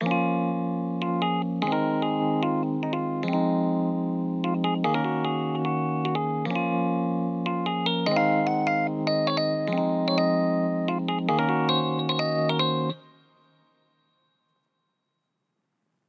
Paranoid 149 BPM A#m.wav